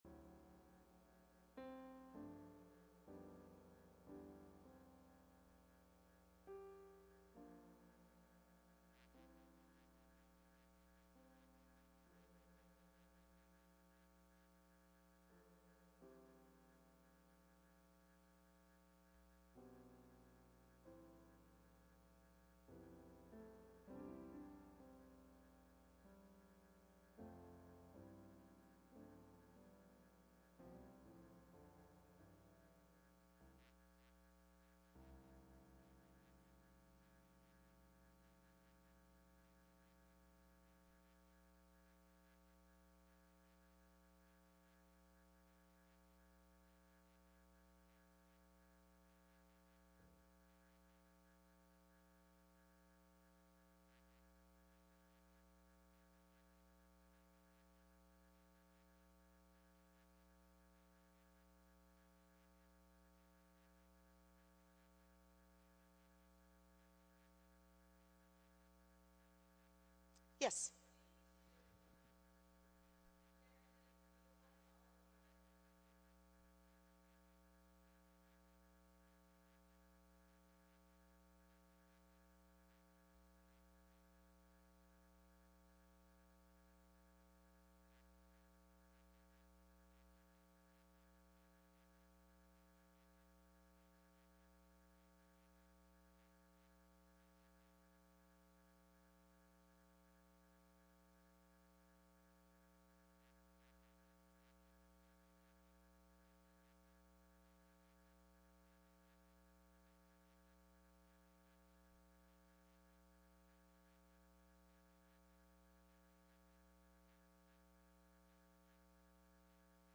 Workshop
From The 2007 Calvary Chapel Worship Life Conference themed Give Me Jesus.